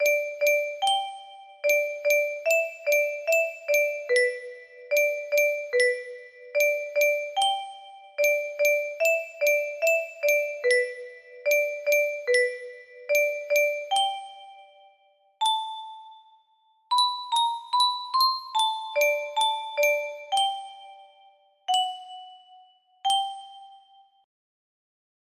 My grandfather's clock music box melody